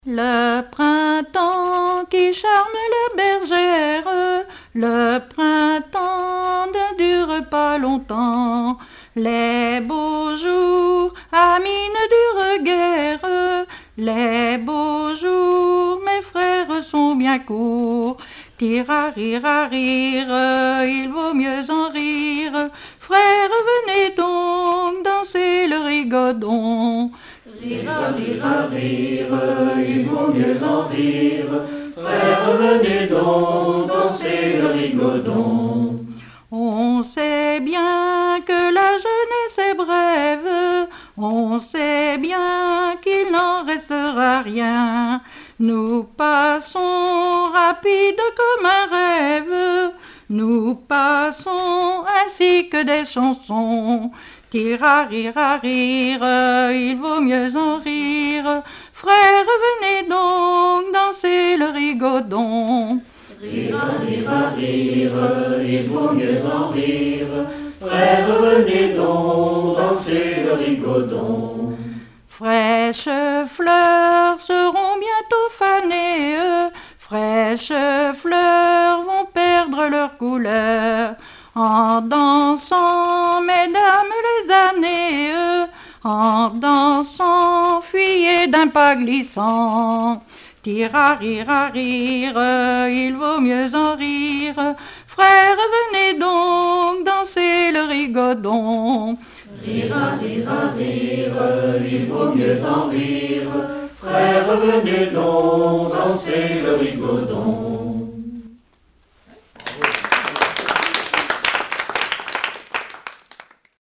Genre strophique
Collectif de chanteurs du canton - veillée (2ème prise de son)
Pièce musicale inédite